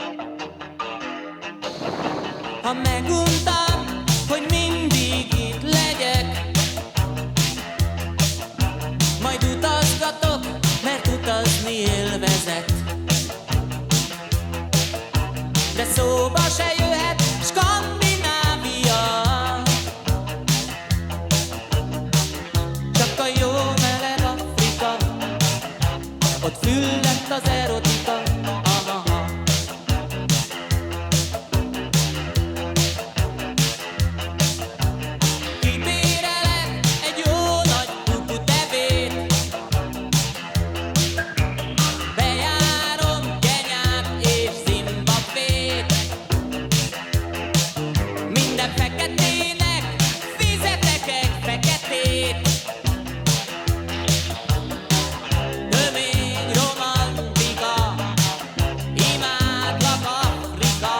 Minőség: 320 kbps 44.1 kHz Stereo